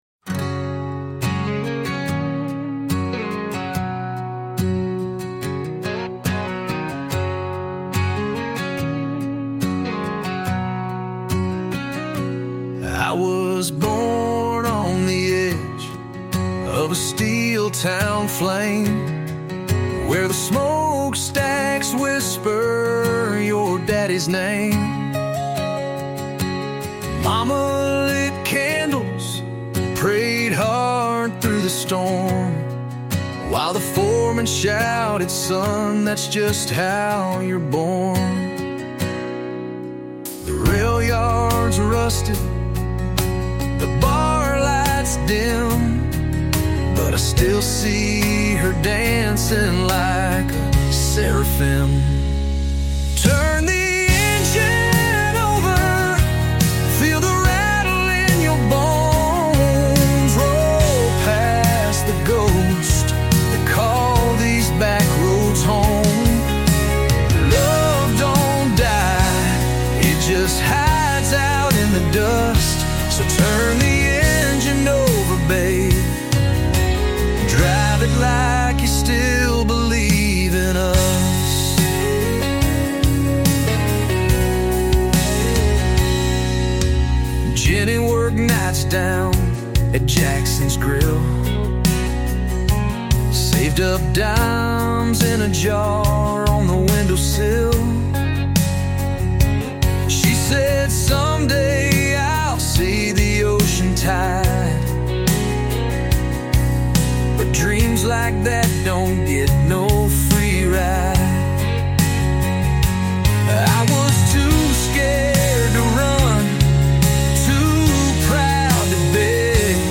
I had Suno (different AI application) gen up a version you can listen to, which wound up a country song and nothing like the Boss, but honestly still pretty awesome.